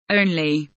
only kelimesinin anlamı, resimli anlatımı ve sesli okunuşu